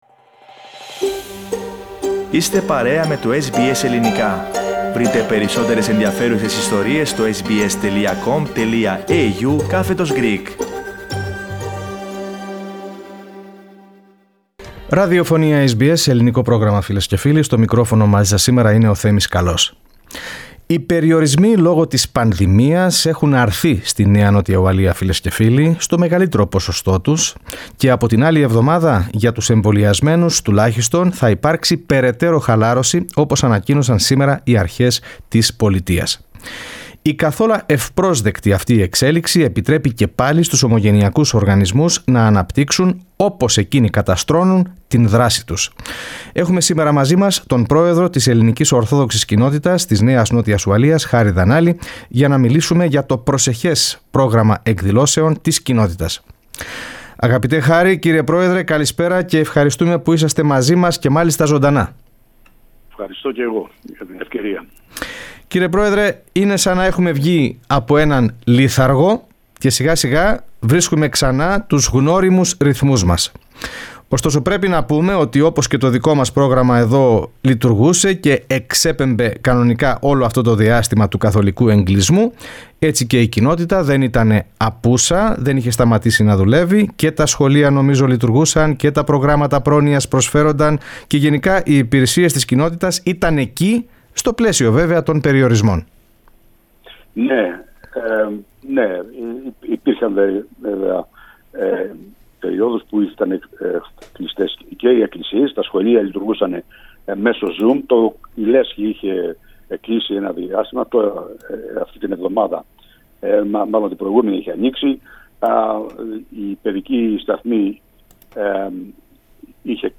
Στην ίδια συνέντευξη